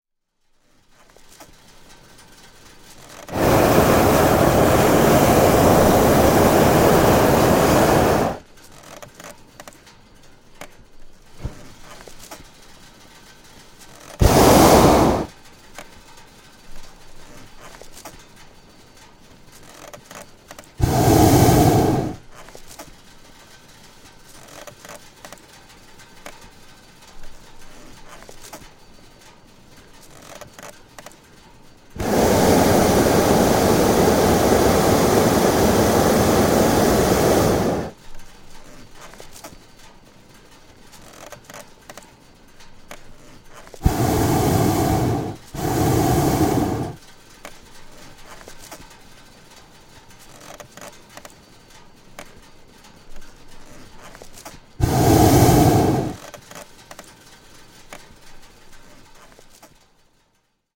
Атмосферные звуки полета на воздушном шаре